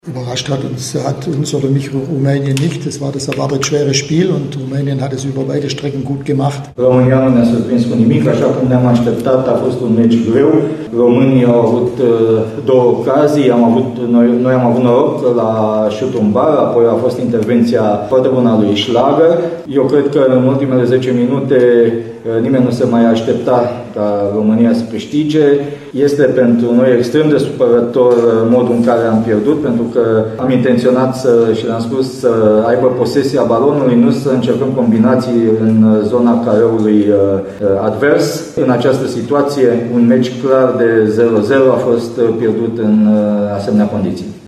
Selecționerul Austriei, Ralf Rangnick, consideră că echipa sa a scăpat de sub control un joc care se îndrepta clar spre 0-0: